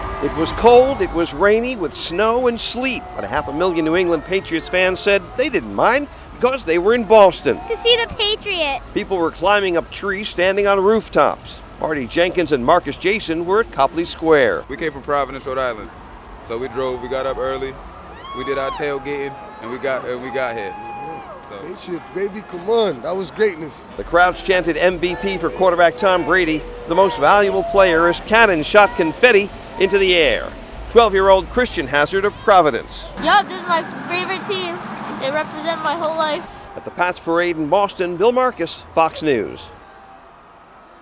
Patriots fans in the trees at Copley Square Tuesday to watch the parade.